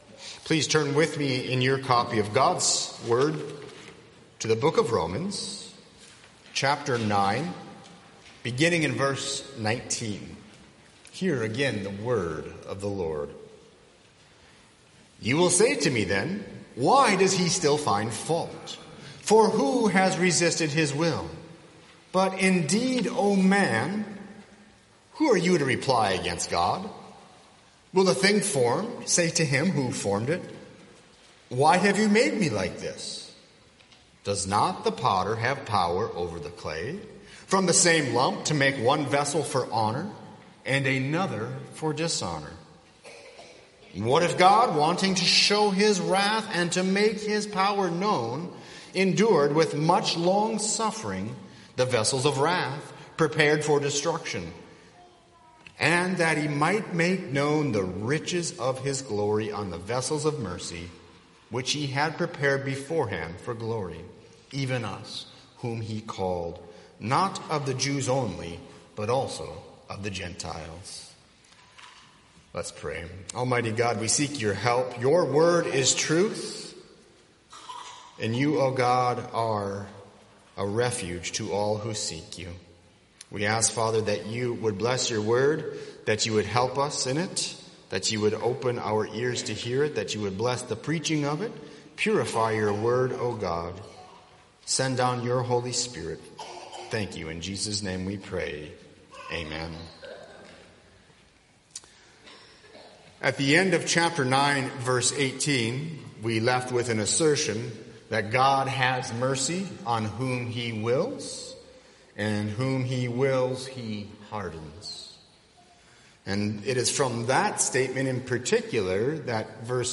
00:00 Download Copy link Sermon Text Romans 9:19–24